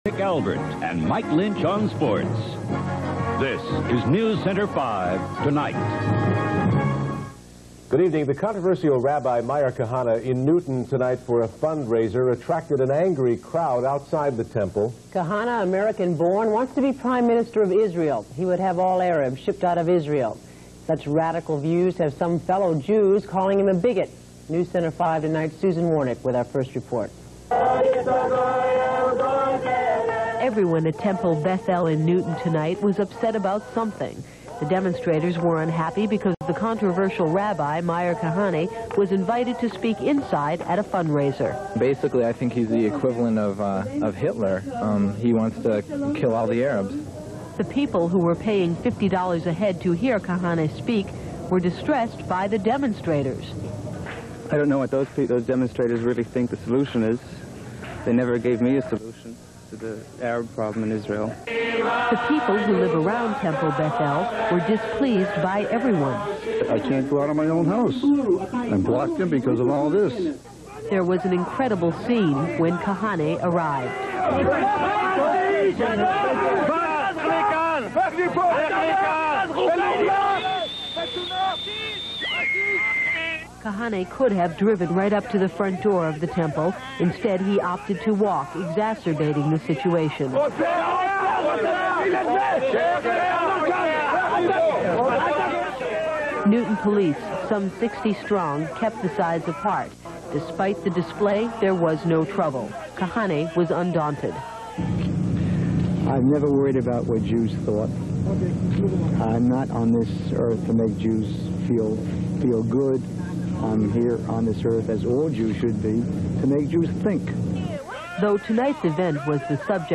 RARE- Rabbi Meir Kahane HYD appears on NewsCenter 5(1).mp3